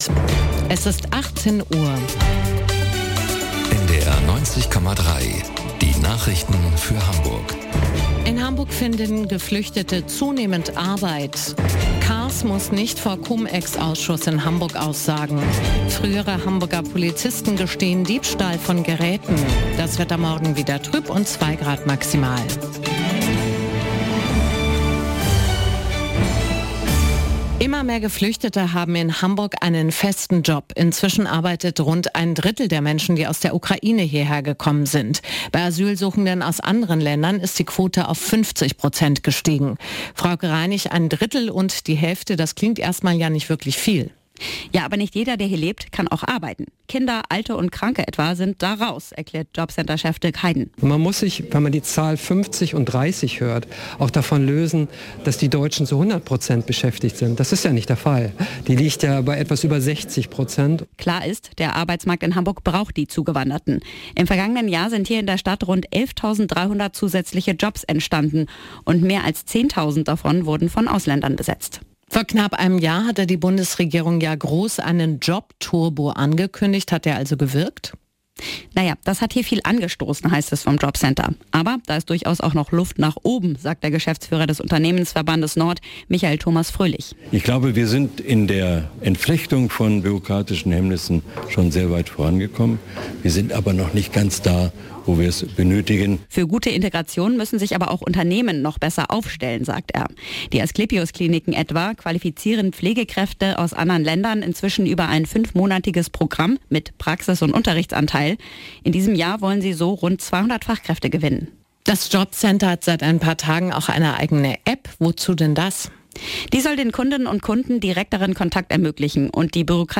1 Nachrichten 6:15